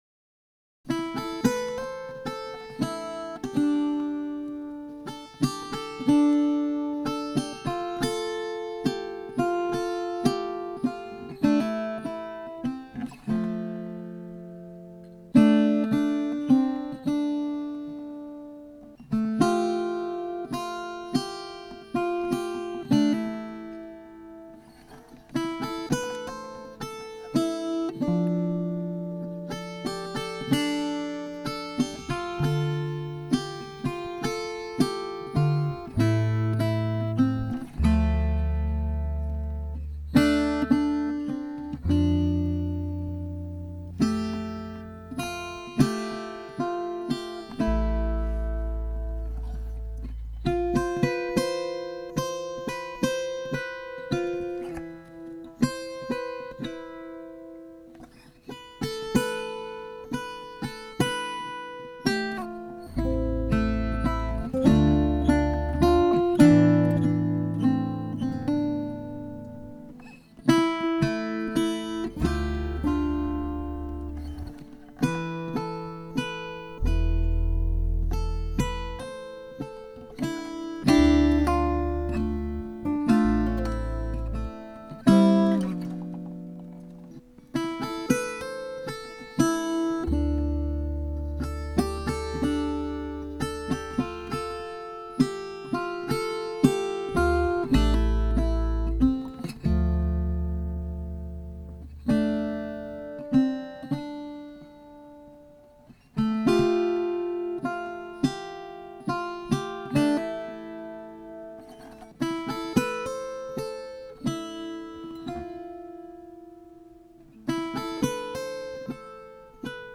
Instrumentals for Guitar
I played the lead on a banjola, an all wooden, teardrop-shaped instrument strung like a banjo.